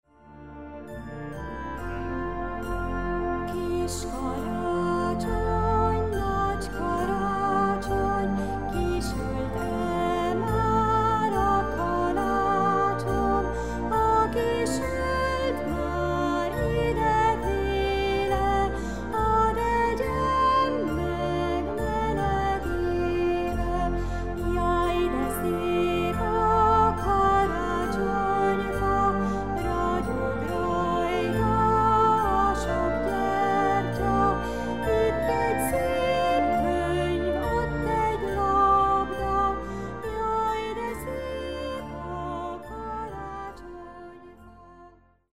ének
karácsonyi dalok babáknak